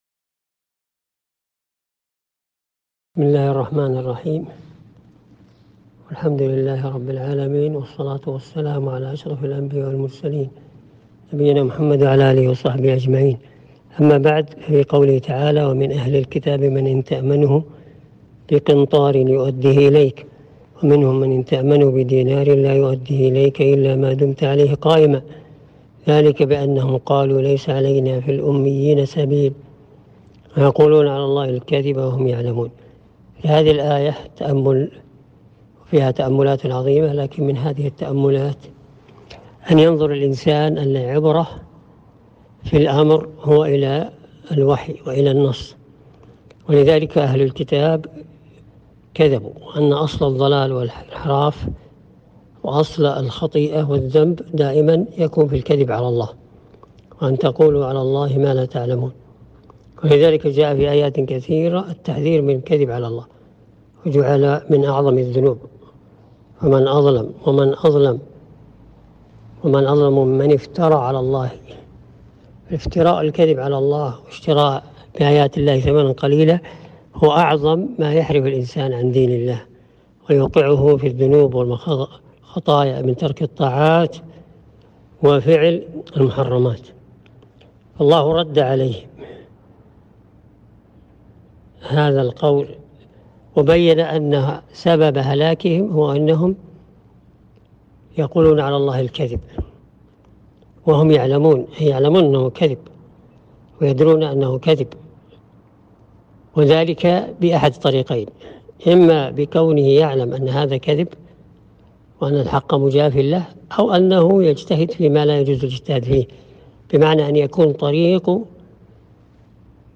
كلمة - تأملات في قوله تعالى ومن اهل الكتاب من ان تأمنه بقنطار